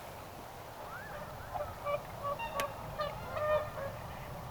onko tuo alussa oleva
vihellys kyhmyjoutsenen
Se ei ole ihmisen tuottama tai
ihmisen laitteen ääni tietääkseni.
onko_tuo_alussa_oleva_aani_kyhmyjoutsenen.mp3